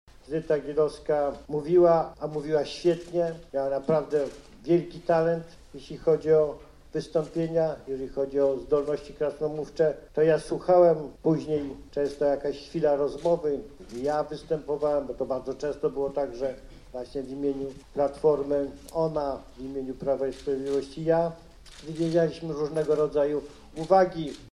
Jarosław Kaczyński przyjechał wczoraj do Lublina, aby wziąć udział w konferencji poświęconej jej pamięci.
– mówi Jarosław Kaczyński